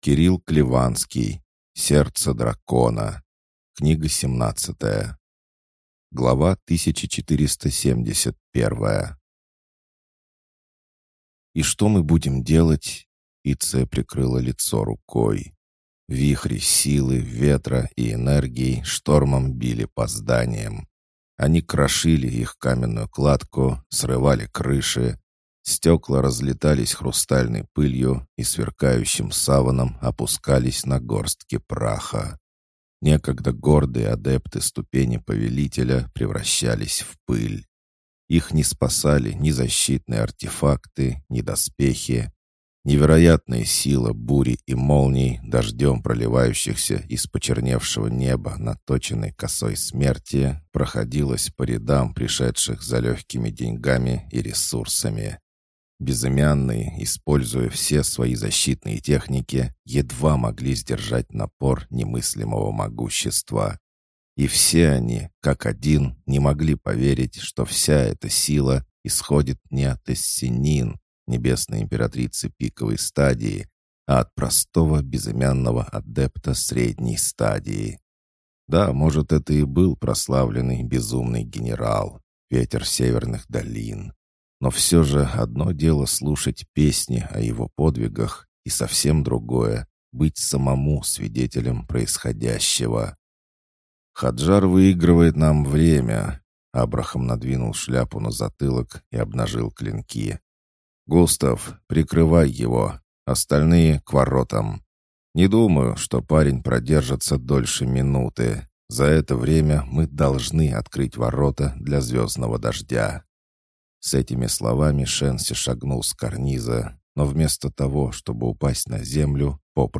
Аудиокнига Сердце Дракона. Книга 17 | Библиотека аудиокниг